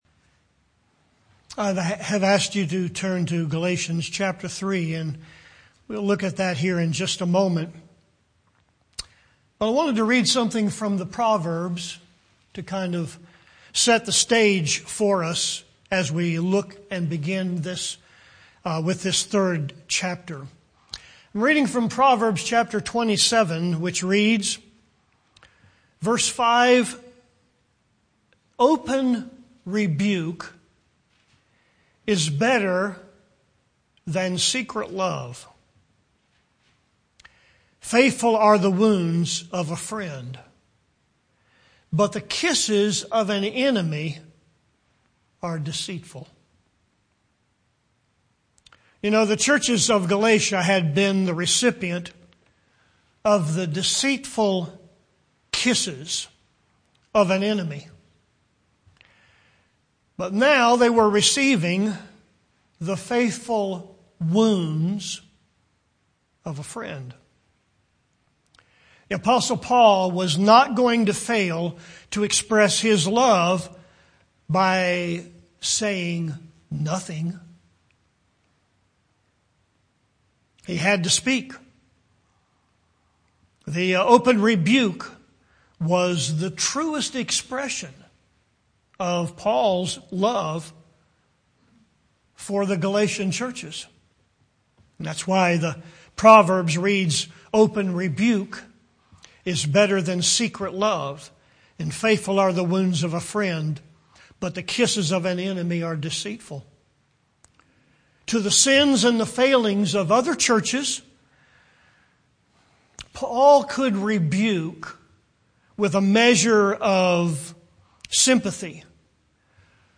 Grace Fellowship Baptist Church, Arden, NC